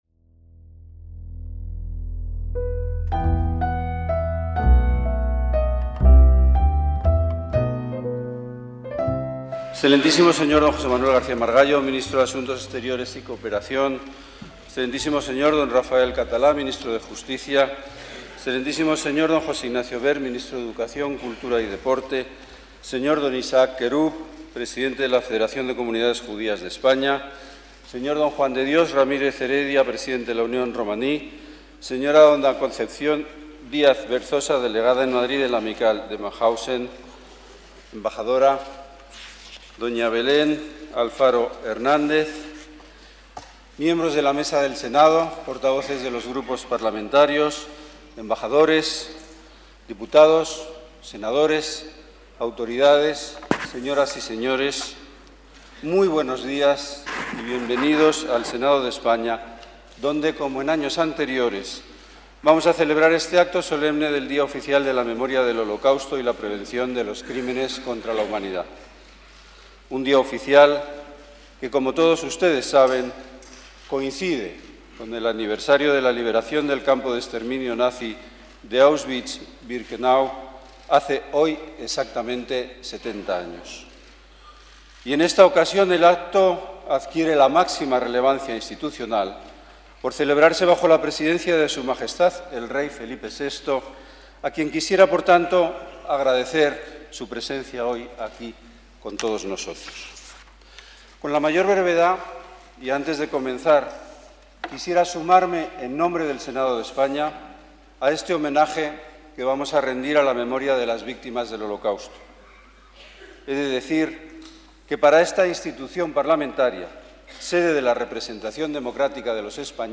ACTOS EN DIRECTO - El Rey Felipe VI presidió el Día Oficial de la Memoria del Holocausto y la Prevención de los Crímenes contra la Humanidad en el Antiguo Salón de Sesiones del Senado. El presidente del Senado, Pío García Escudero; el ministro de Asuntos Exteriores, José Manuel García Margallo; el ministro de Justicia, Rafael Catalá; y el ministro de Educación, Cultura y Deporte, José Ignacio Wert han participado en la ceremonia.